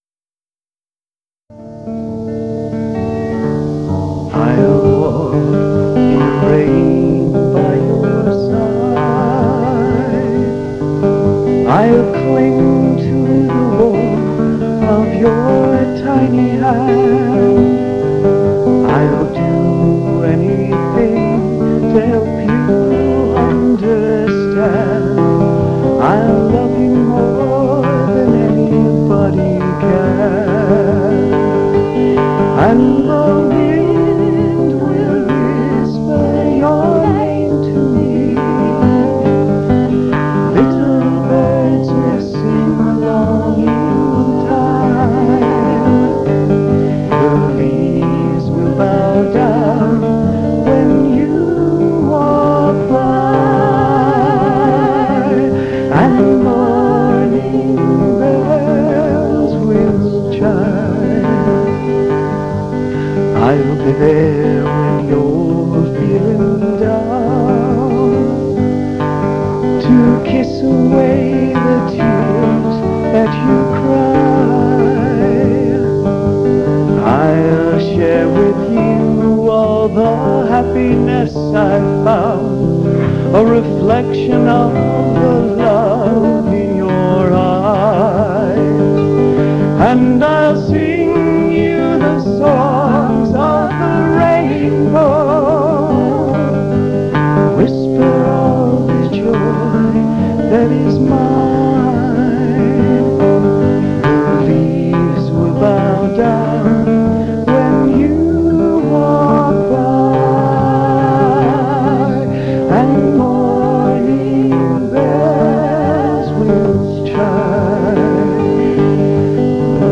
folk harmonies
guitarist/accompanist